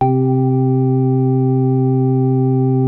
HOUSY ORG 1.wav